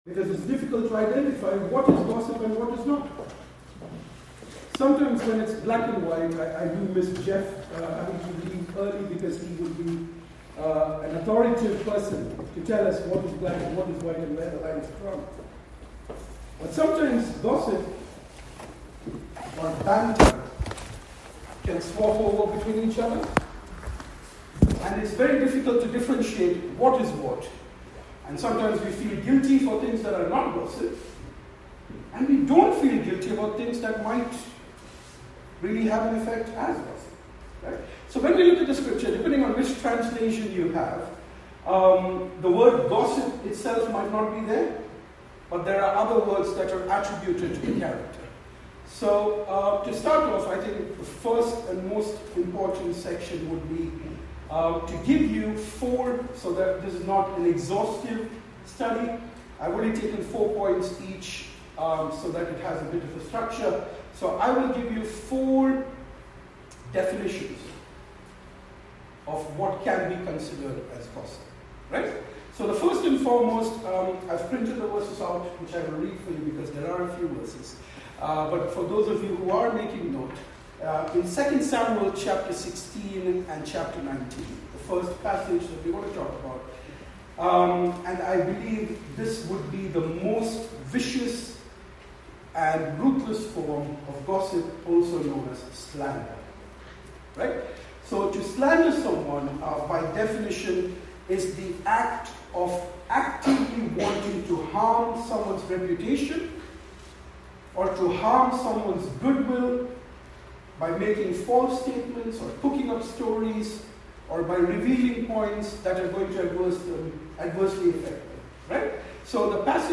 Our apologies for the recording quality as their was an issue with the recording equipment.